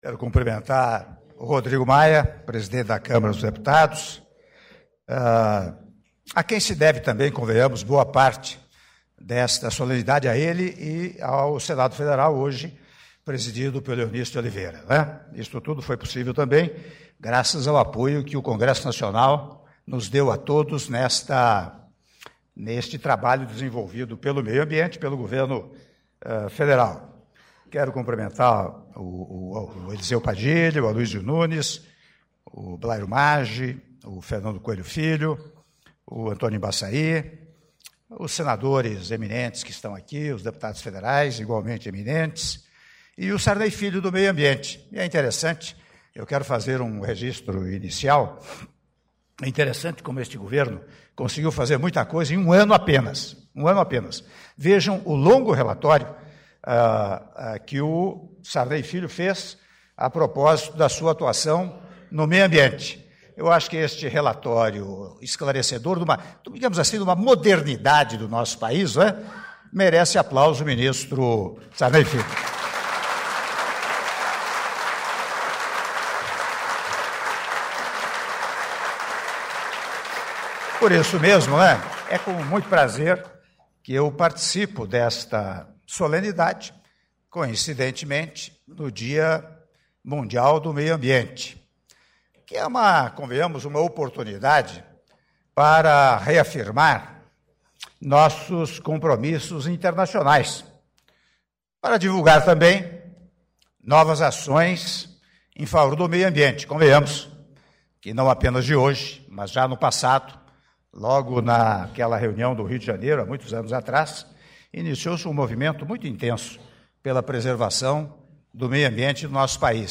Áudio do discurso do presidente da República, Michel Temer, durante cerimônia em comemoração ao Dia Mundial do Meio Ambiente- Brasília/DF- (08min42s)